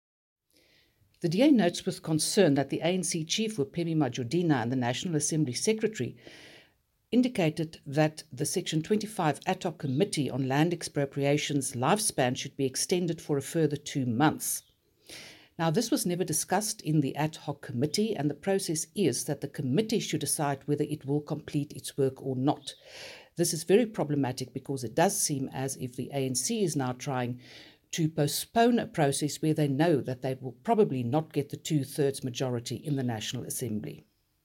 Afrikaans soundbites by Dr Annelie Lotriet MP.